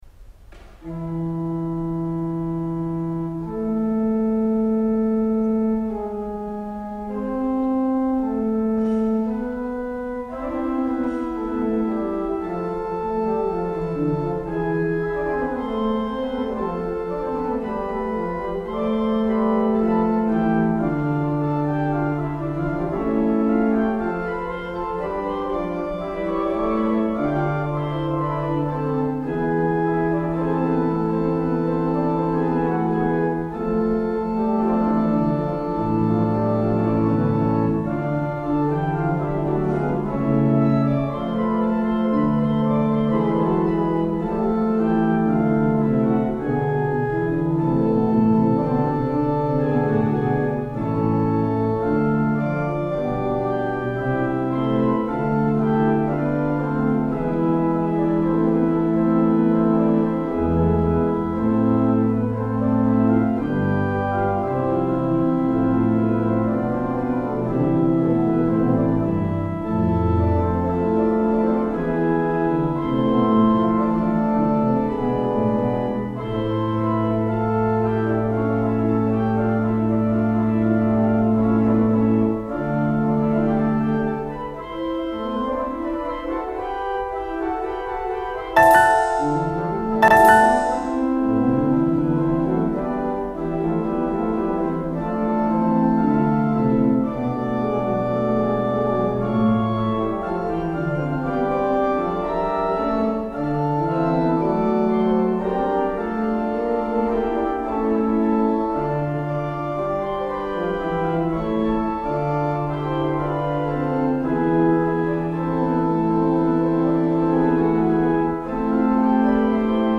Group: Instrumental